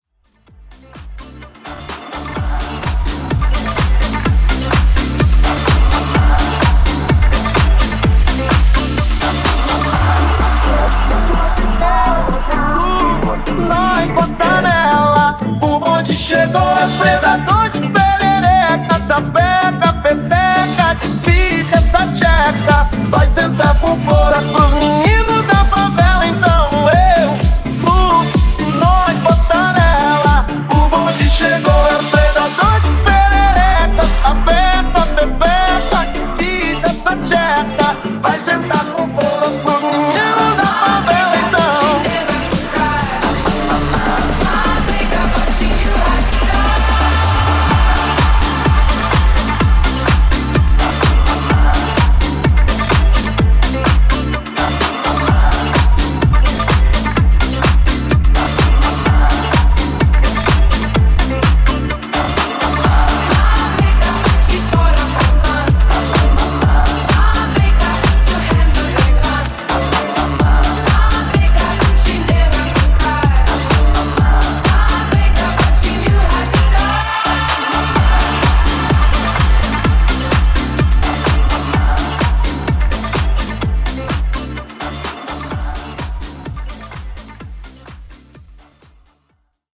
Mashup Bootleg)Date Added